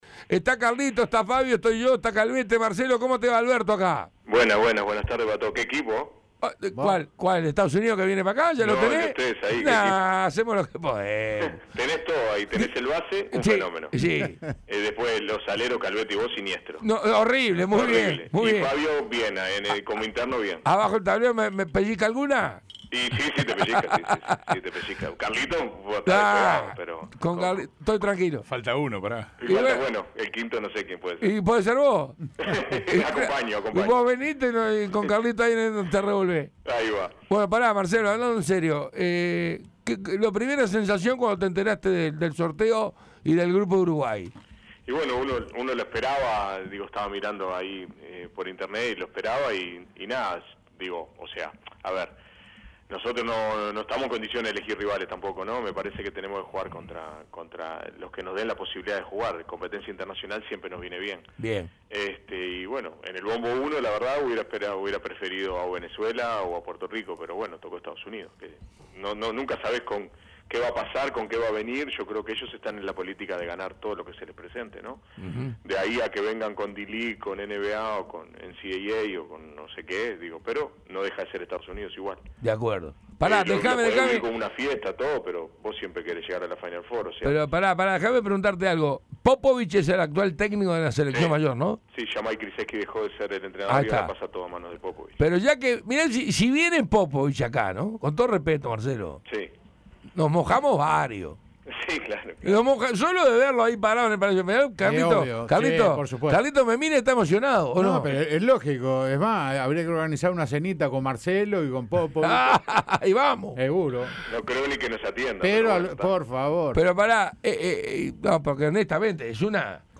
Entrevista completa.